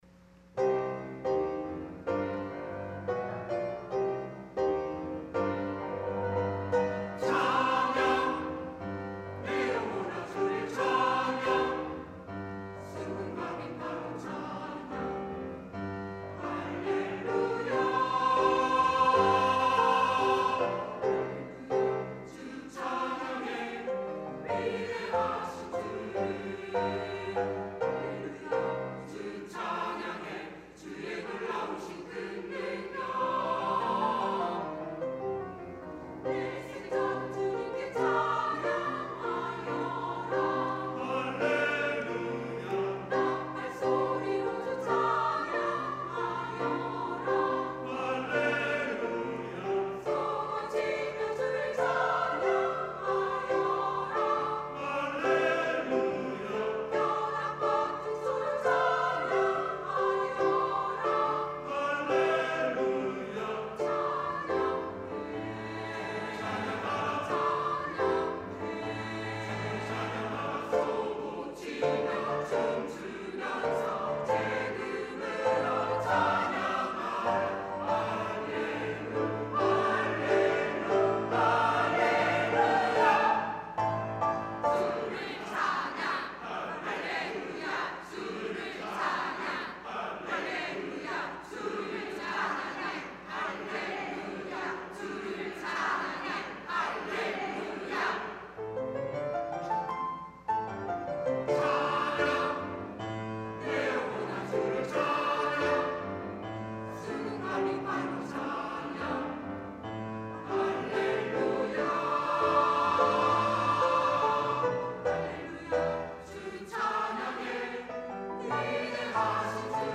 찬양 :: 141012 할렐루야 주를 찬양
" 할렐루야 주를 찬양 "- 시온찬양대